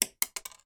Звуки зубов
Шум упавшего на стол человеческого зуба